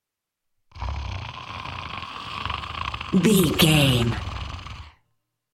Monster growl snarl small creature 217
Sound Effects
scary
eerie
angry